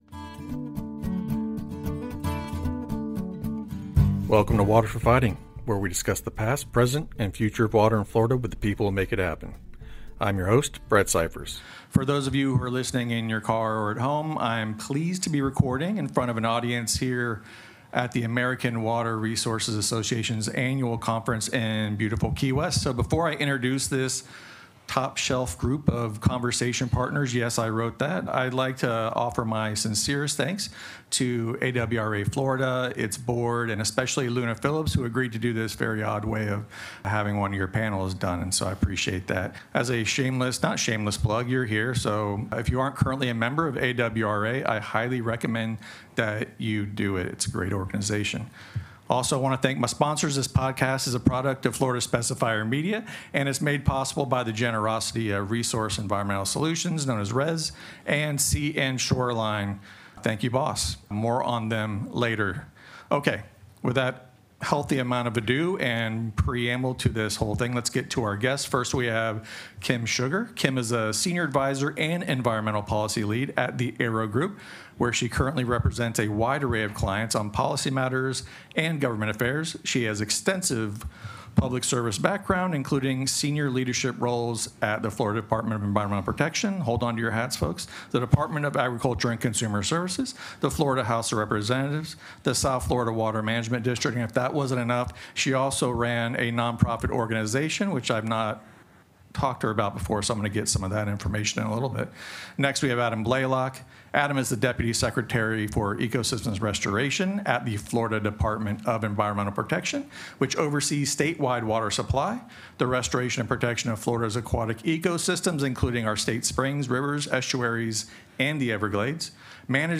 This special joint episode from Water for Fighting and the Florida Specifier Podcast—a product of the Specifier Media Group—was recorded in front of a live audience at the American Water Resources Association’s annual conference in Key West.